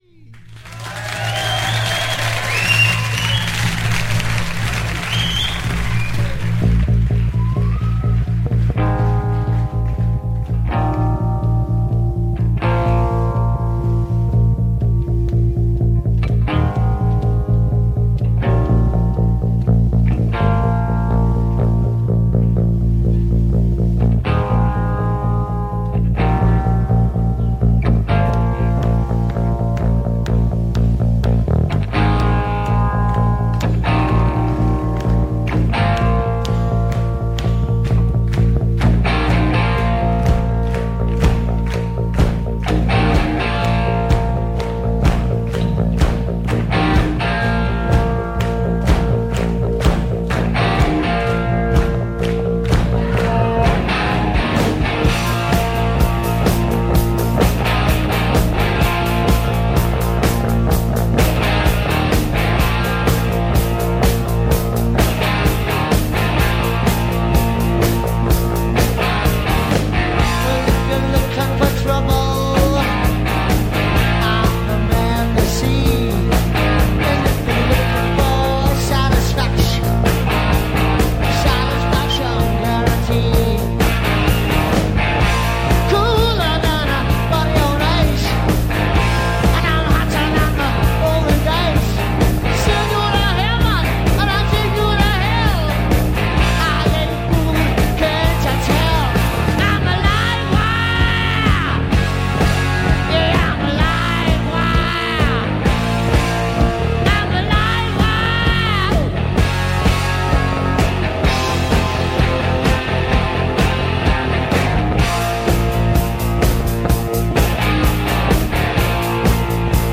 Headbangers of the 70s.
Hard Rock